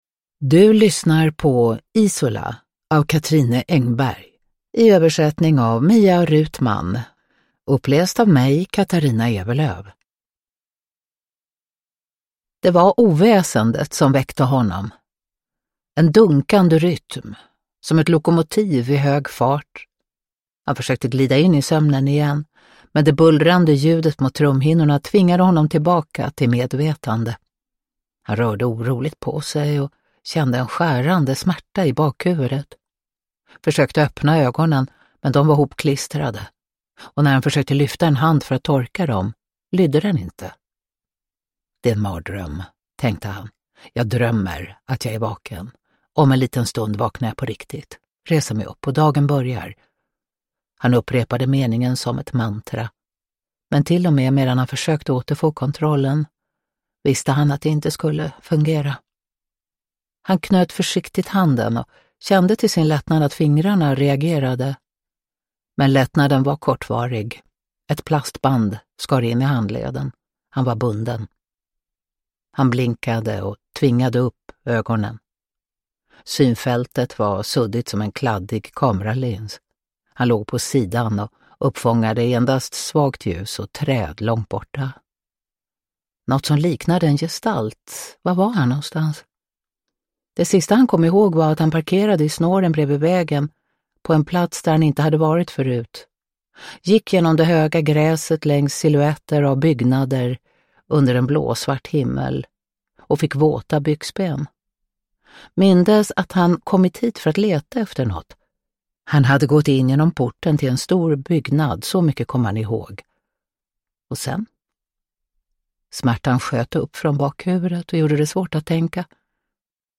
Isola – Ljudbok – Laddas ner
Uppläsare: Katarina Ewerlöf